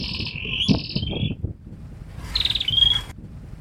Cambodian Tailorbird
Preaek Ta Sek, Chroy Changvar District, Phnom Penh, Cambodia
Orthotomus chaktomuk
Tailorbird Cambodian PREAEK TASEK PP KHM song [B] ETSJ_LS_71474.mp3